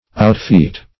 Outfeat \Out*feat"\ (out*f[=e]t"), v. t. To surpass in feats.